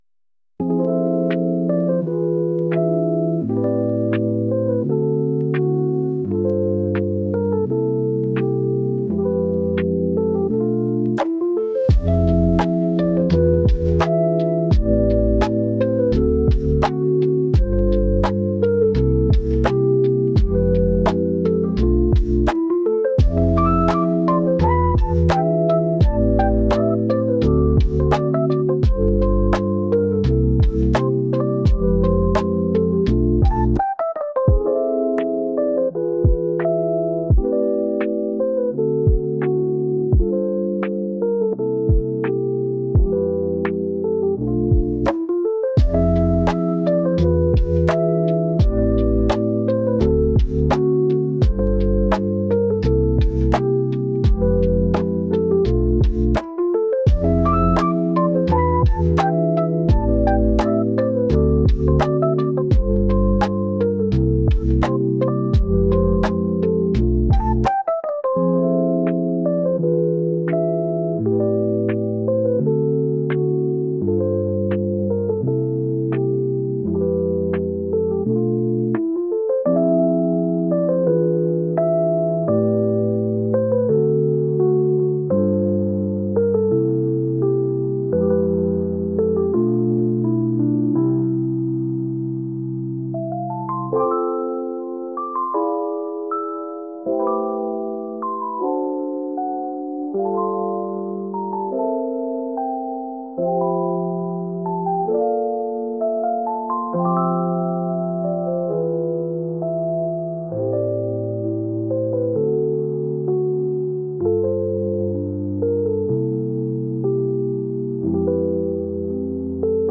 夜中に追い込みをかけるためのゆったりした曲です。